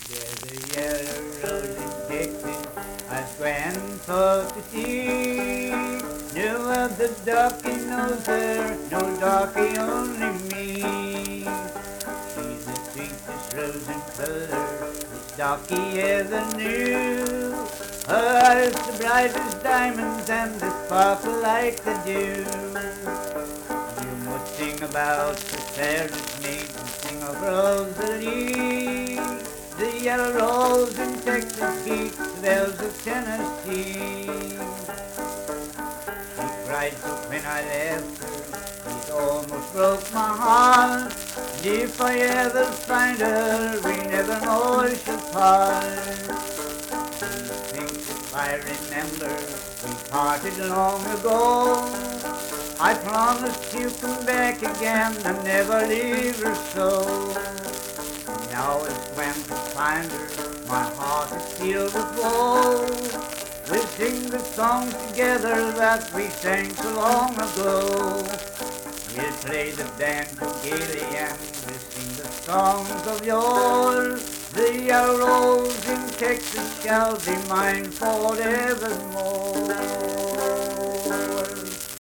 Banjo accompanied vocal music performance
Love and Lovers, Minstrel, Blackface, and African-American Songs
Voice (sung), Banjo